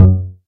noteblock_bass.wav